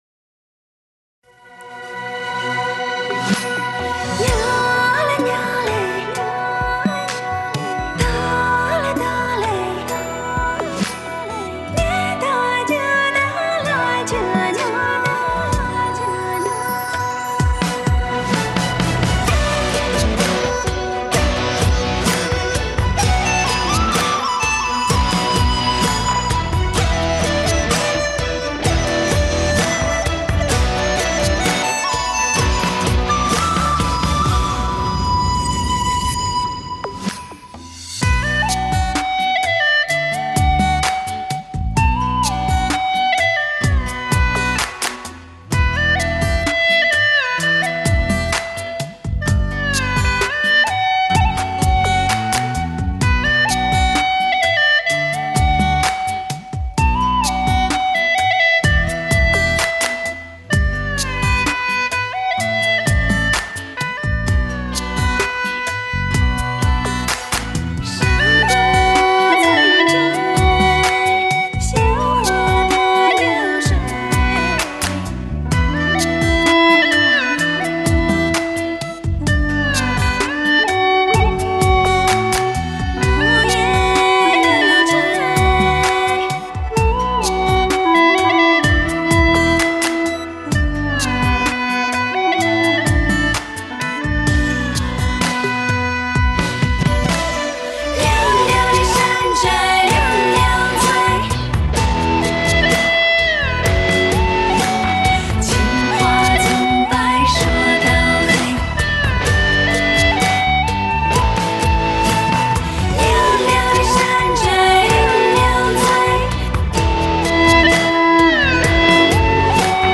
调式 : D 曲类 : 民族